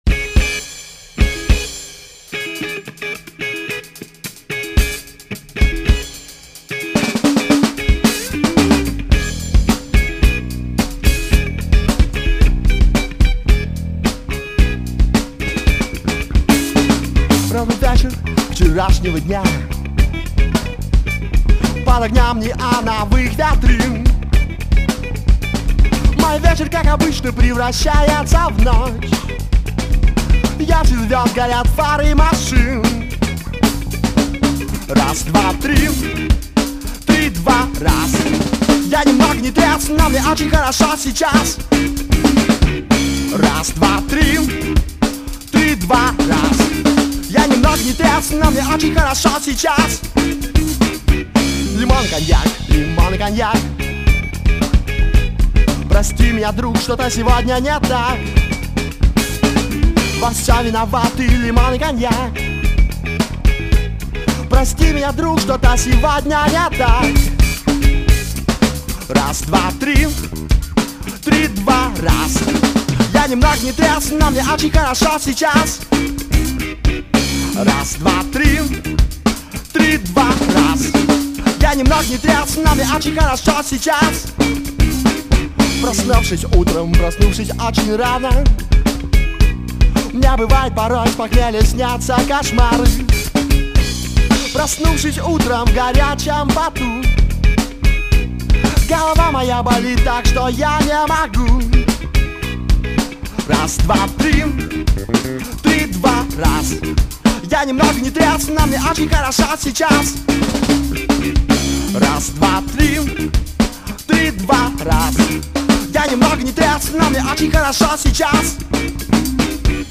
пока это записи живых выступлений группы
Демо Запись